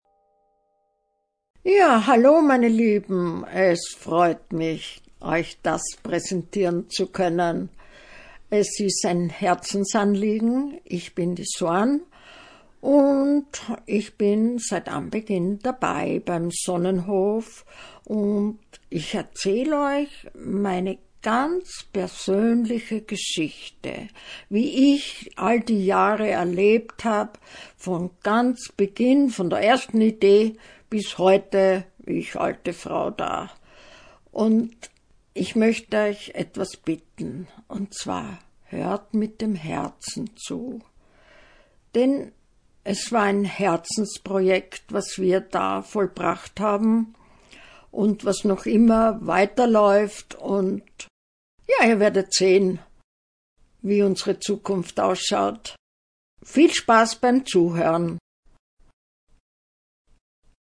Eine kleine Kostprobe vom Audiobuch: